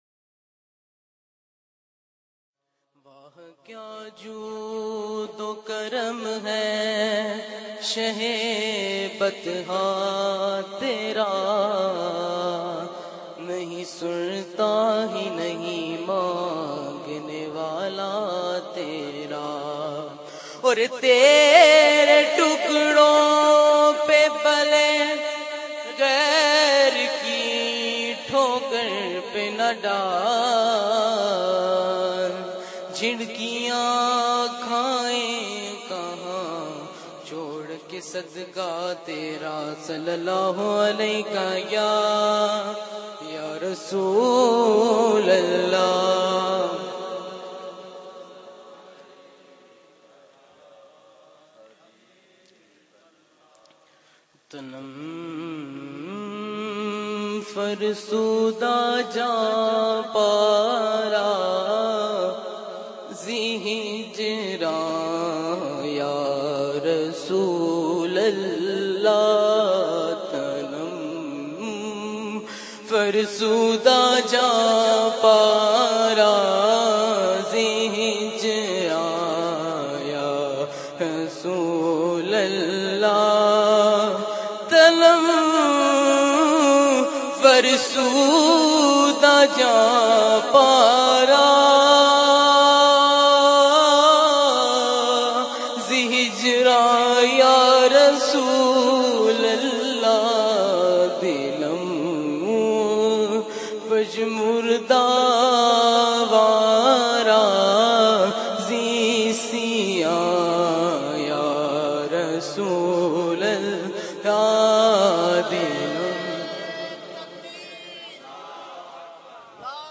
آڈیو نعتیں